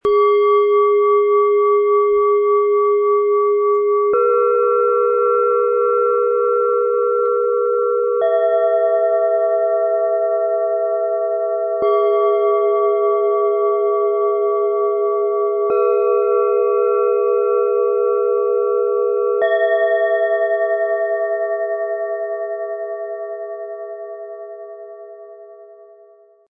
Lichtklang - Harmonie im Aufstieg - Set aus 3 Klangschalen in schwarz-gold, Ø 10,6 - 12,7 cm, 1,01 kg
Freude, Leichtigkeit, Licht und Ruhe - dieses Set entfaltet eine wohltuende Klangreise vom sanften Erdton bis zu hellen, lichtvollen Schwingungen.
Die tiefste Schale öffnet einen Raum der inneren Ruhe, die mittlere bringt Harmonie und Verbindung hinein, während die kleinste Schale mit ihrem klaren Ton Leichtigkeit und Freude schenkt.
Zusammen erzeugen die Schalen einen aufsteigenden Klangbogen, der sich sanft entfaltet und das Herz berührt. Die Übergänge sind fließend, die Stimmung bleibt hell und freundlich - wie ein Morgenspaziergang im Licht.
Die Schalen entfalten gemeinsam einen aufsteigenden, lichtvollen Klangbogen, der sanft das Herz berührt.
Der passende Klöppel ist im Lieferumfang enthalten und lässt das Set angenehm weich und harmonisch erklingen.
Bengalen Schale, Schwarz-Gold, 12,7 cm Durchmesser, 6,2 cm Höhe